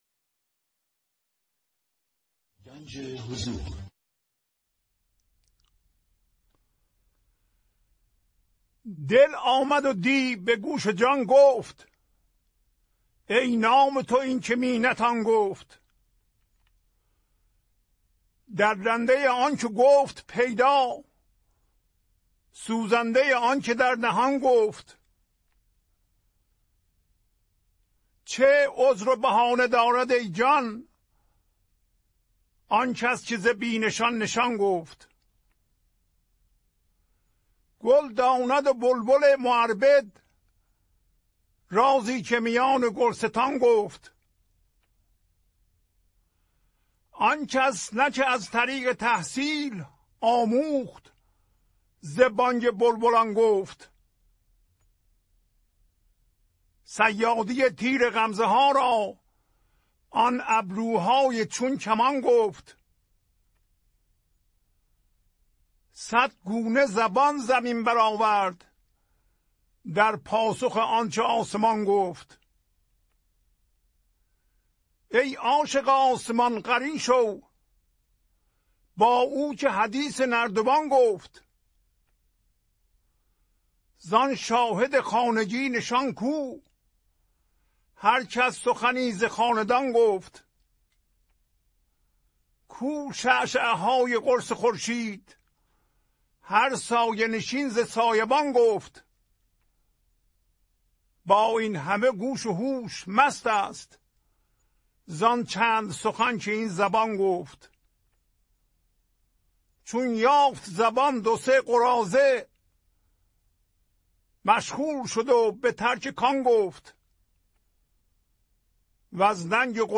خوانش تمام ابیات این برنامه - فایل صوتی
1020-Poems-Voice.mp3